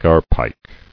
[gar·pike]